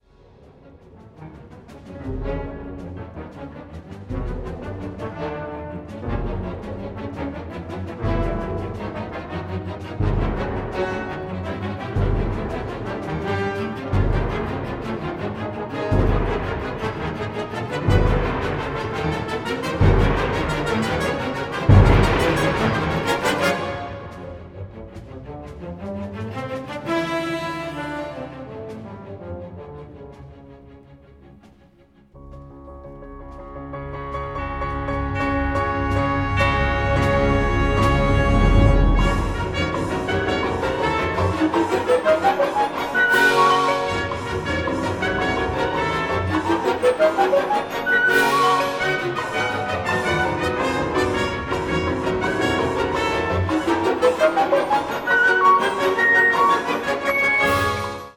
piano
ondes martenot
symphony for piano, ondes martenot and orchestra
Modéré, presque vif, avec une grande joie